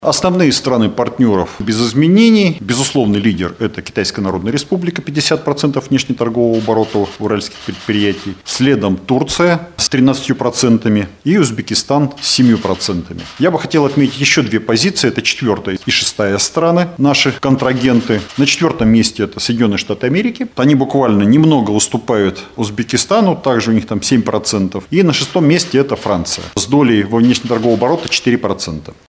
На втором — Турция с 13%, на третьем — Узбекистан с 7%.Статистику за 2025 год привел начальник Уральского таможенного управления Алексей Фролов на пресс-конференции в центре ТАСС-Урал.